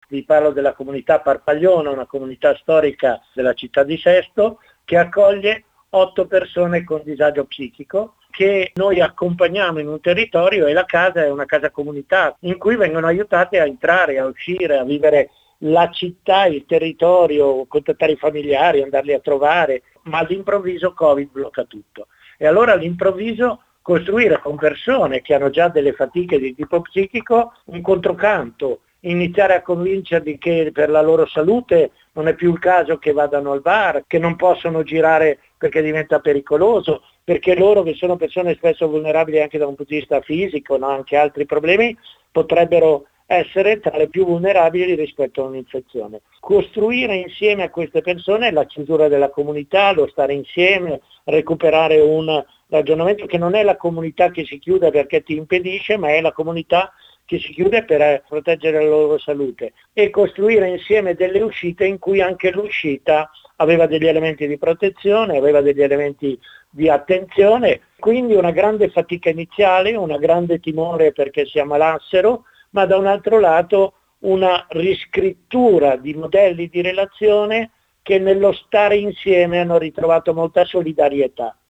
Ai nostri microfoni racconta un caso emblematico per quanto riguarda l’impatto della pandemia su questo tipo di strutture: parliamo di una comunità di Sesto San Giovanni, alle porte di Milano: